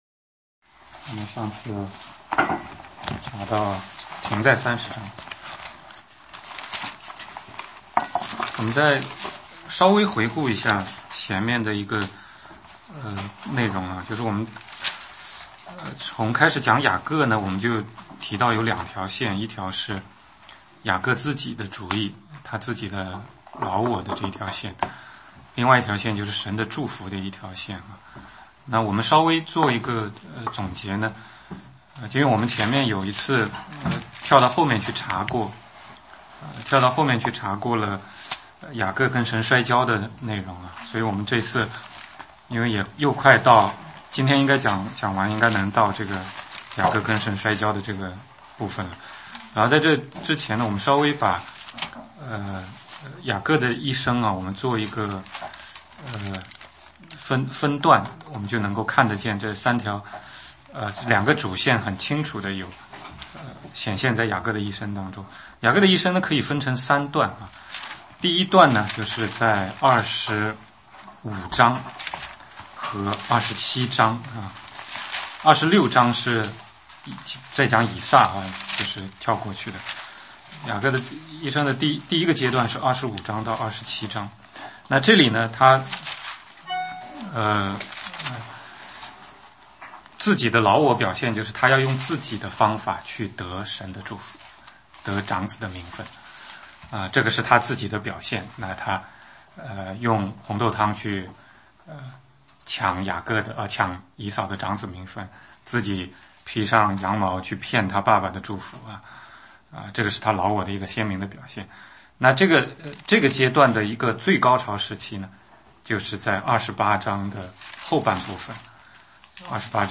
16街讲道录音 - 创世纪35雅各回伯特利（灵性的回归）